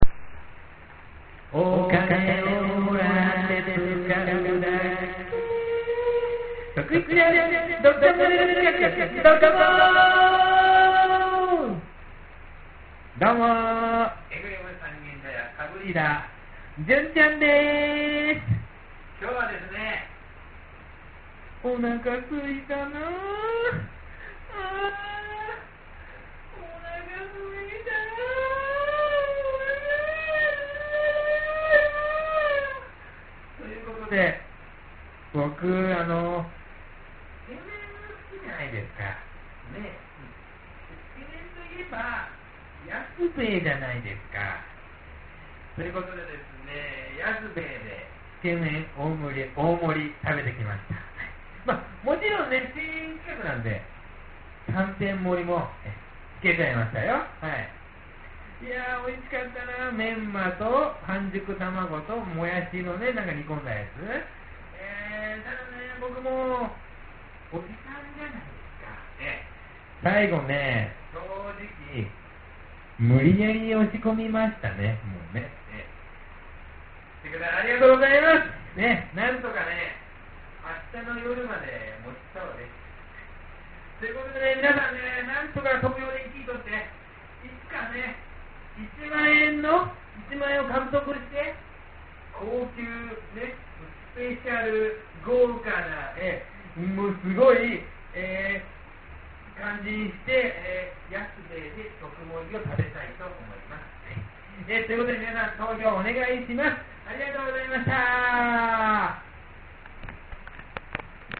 今日も音録りはカラオケボックスでビール片手に思いっきりエコーきかせて・・・録音レベル失敗してますよ^^;
リポート等の音声はスタジオ録音ではなく現地録りになりますので他の人の声などの雑音が入っていたりしますがご了承願います。